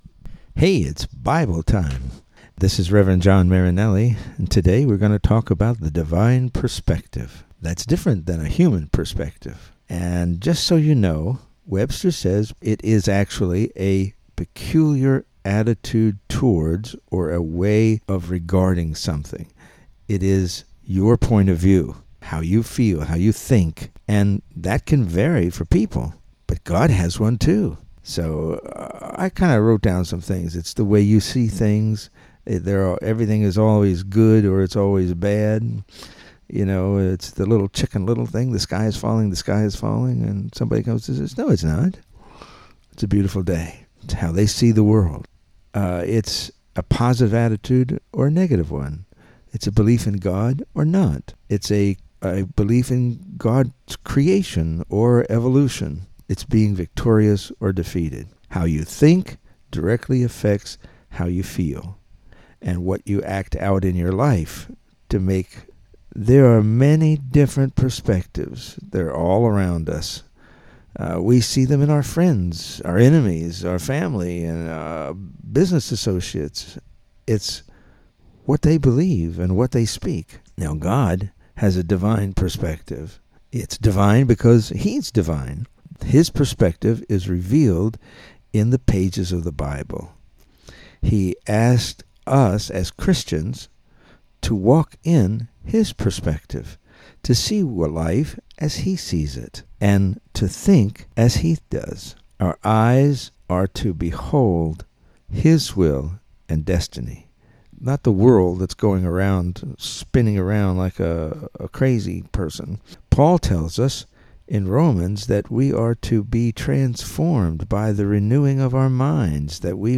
Welcome to Bible Time. The audio files are all 3-6 minute devotional messages that are meant to encourage and